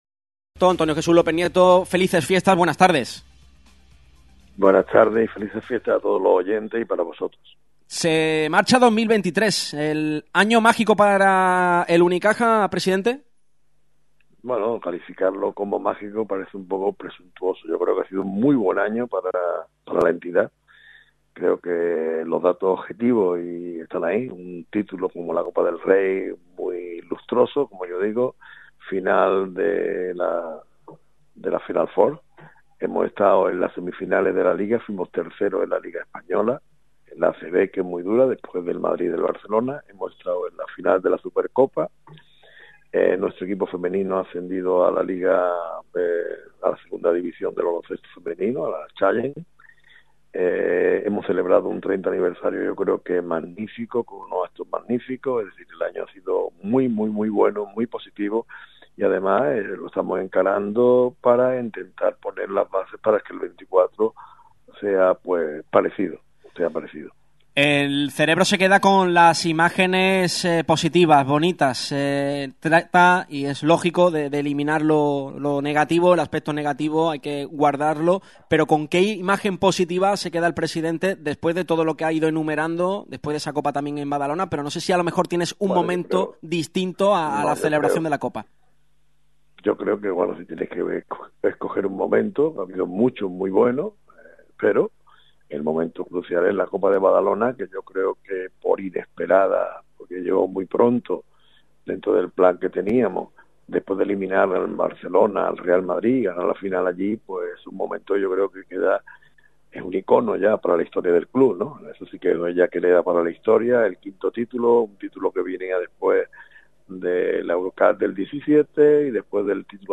López Nieto hablando en Radio Marca Málaga con Radio Marca Málaga
Antonio Jesús López Nieto, presidente de Unicaja Baloncesto, lanza la pregunta e invita a la afición cajista a soñar con una temporada de títulos para los de Ibon Navarro. El máximo mandatario del club de Los Guindos habló para el micrófono de Radio Marca Málaga.